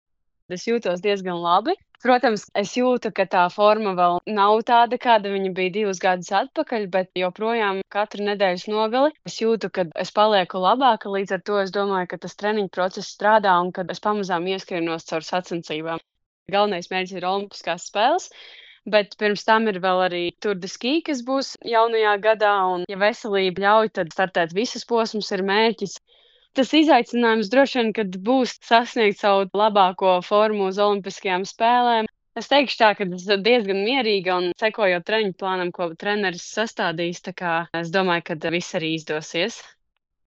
Saruna ar Latvijas distanču slēpotāju Patrīcijau Eiduku